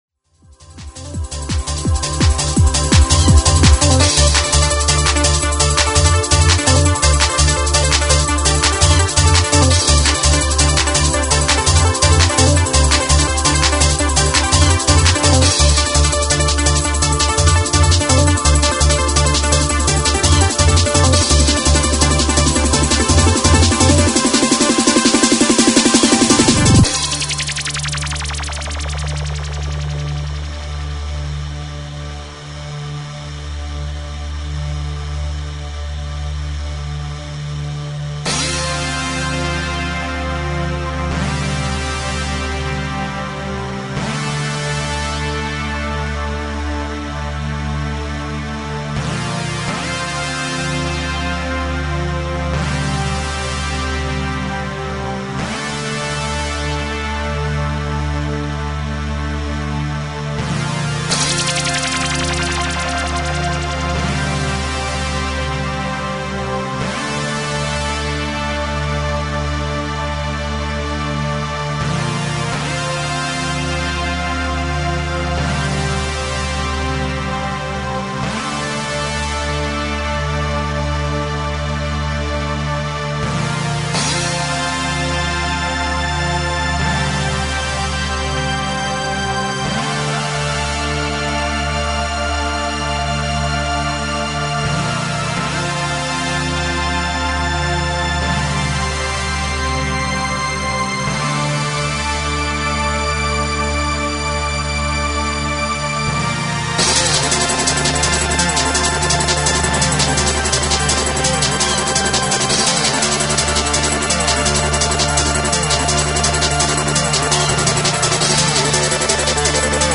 Freeform
Recorded at RSR, Southampton.